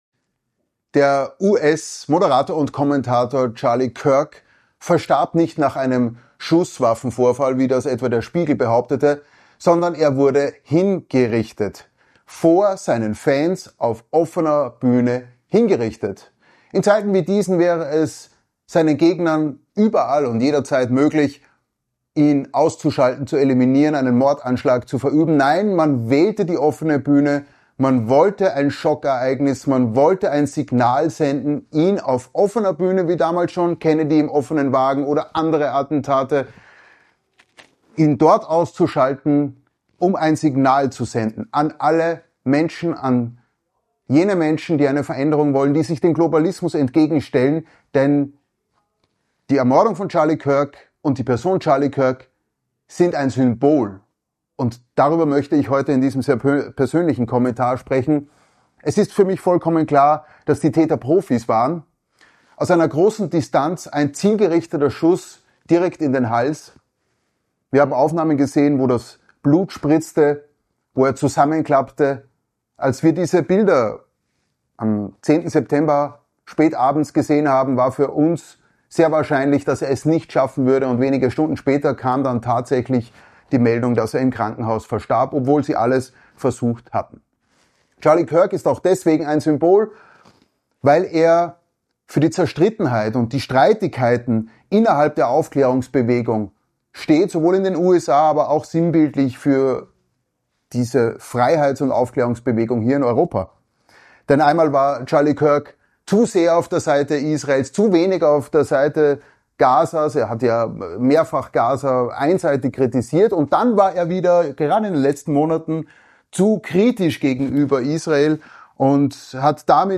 Persönlicher Kommentar